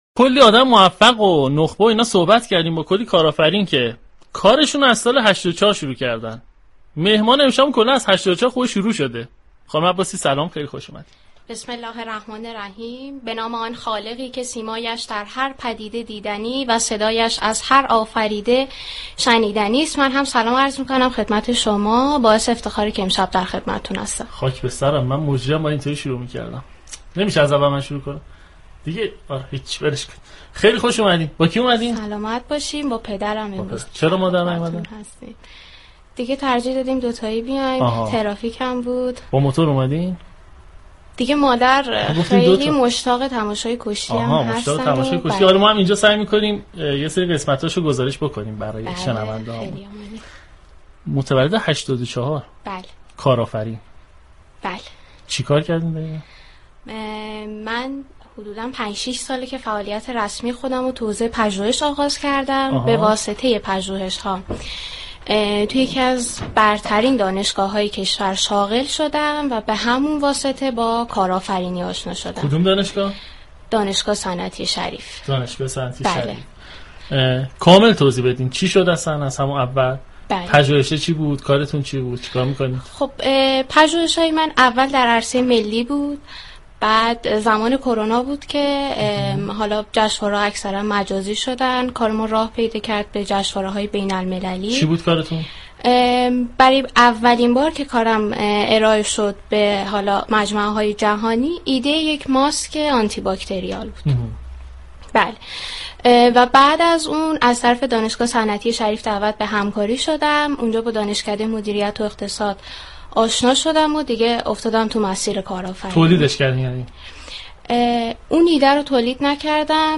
در این برنامه، گفتگویی صمیمانه با این كارآفرین جوان شكل گرفت.